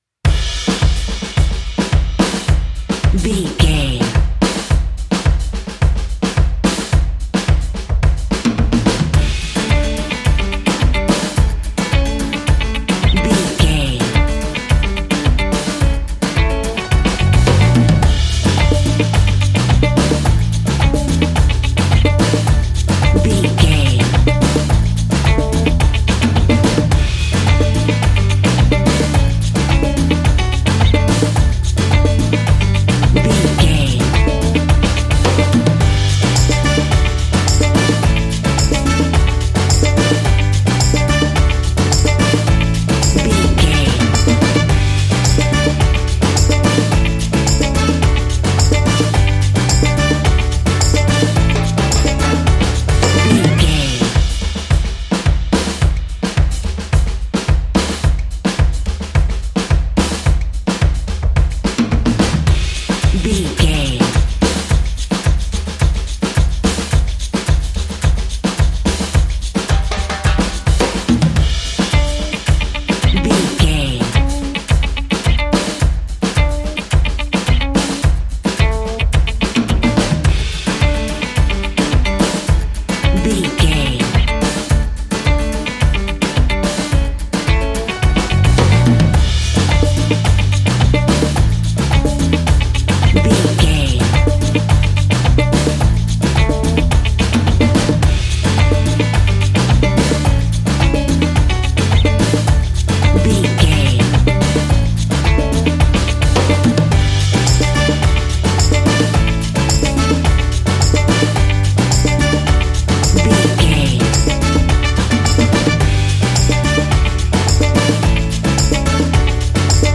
Uplifting
Ionian/Major
steelpan
drums
percussion
bass
brass
guitar